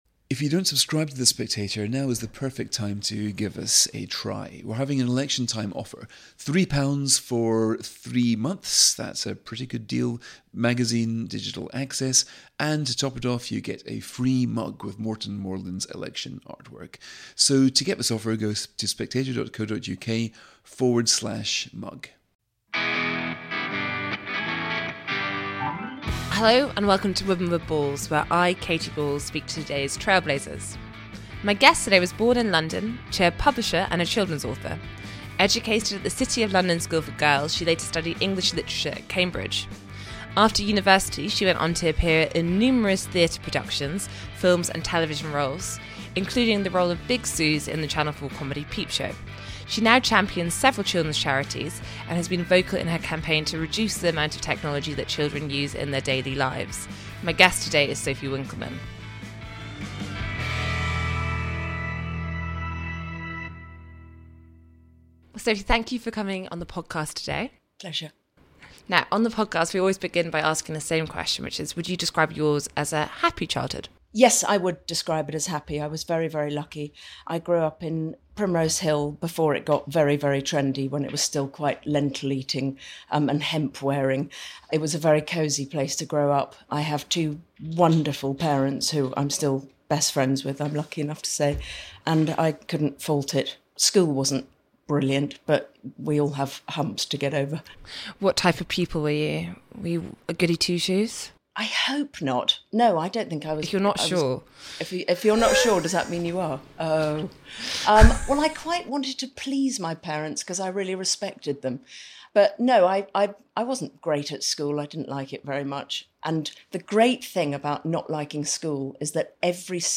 On the episode, Katy Balls talks to Sophie about how she got into acting, whether she has ever dated a Jez or a Mark, and why she believes in the comfort of strangers. Sophie also talks about her campaign to reduce smart phone use and technology exposure for children, which you can read more about here .